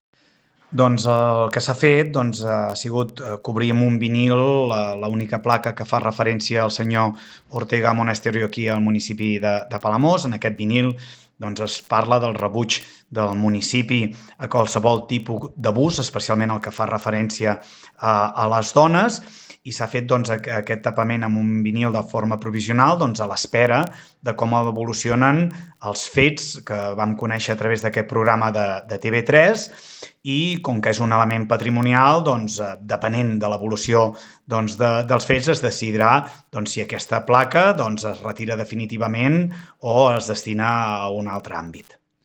L’alcalde del municipi, Lluís Puig, explica a Ràdio Capital que aquesta placa nova es mantindrà tapant l’antiga fins que decideixin com actuar definitivament amb l’original.